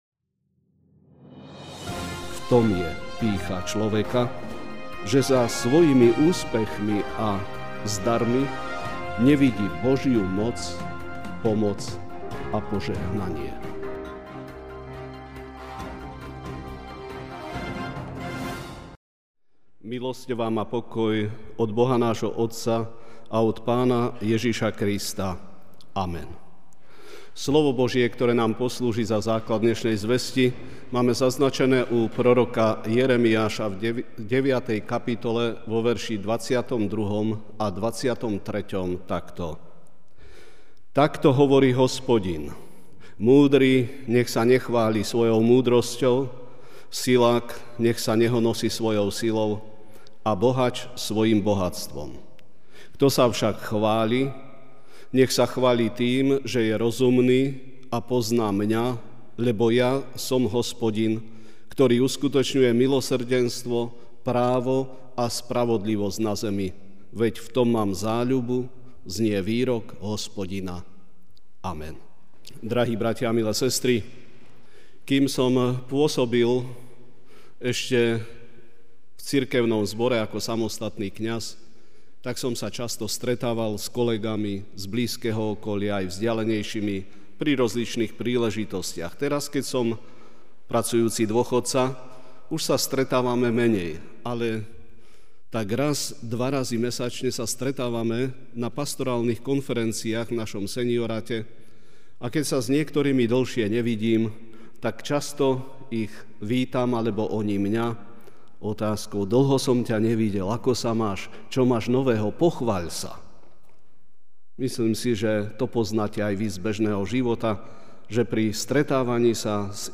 Večerná kázeň: Nechváľ sa ale chváľ Ho!